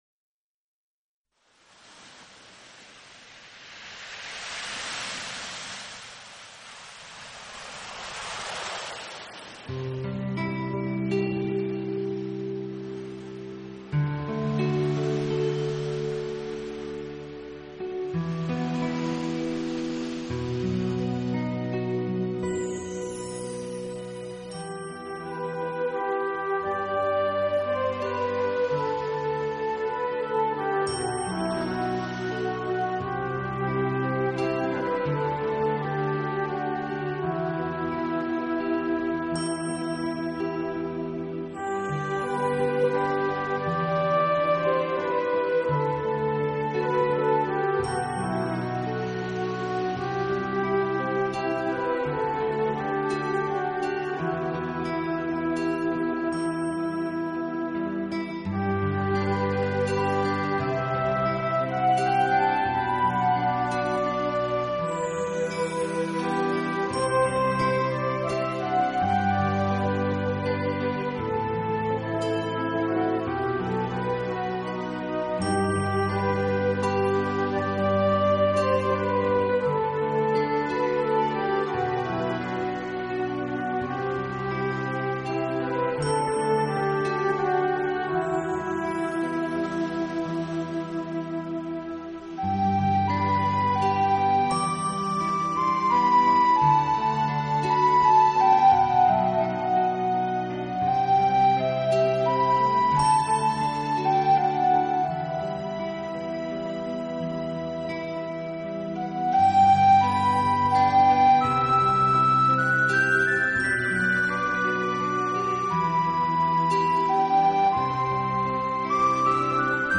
竖琴、双簧管、吉他和电子合成乐器等乐器